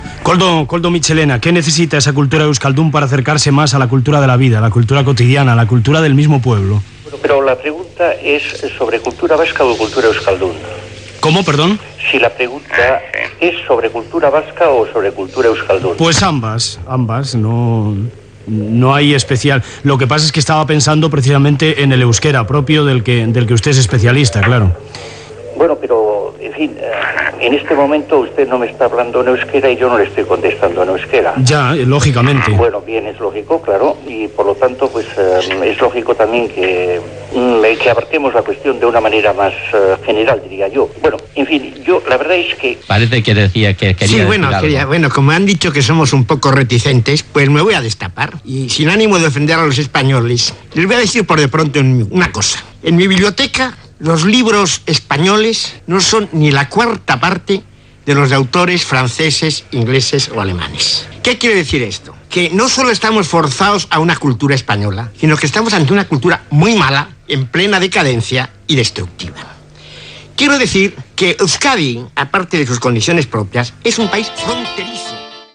Debat sobre la vigència de l'Euskera i la cultura basca amb la intervenció de Koldo Mitxelena i Gabriel Celaya
Entreteniment
FM